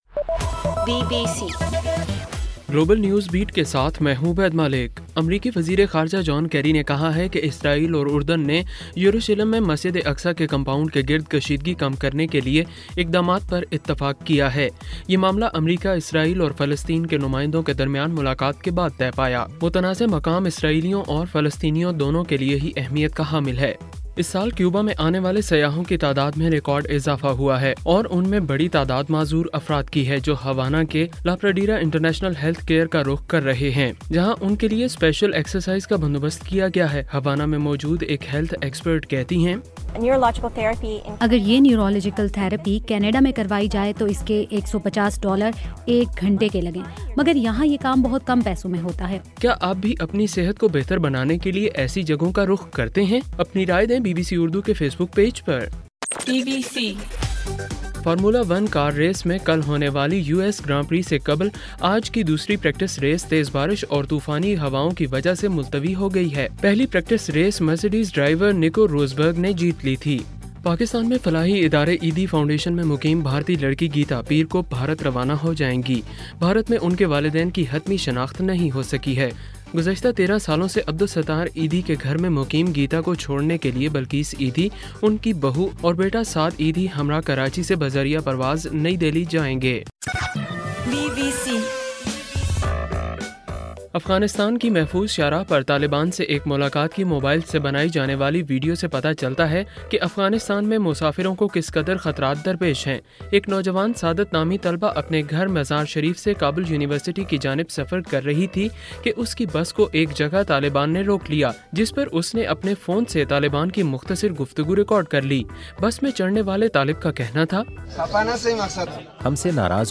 اکتوبر 24: رات 9 بجے کا گلوبل نیوز بیٹ بُلیٹن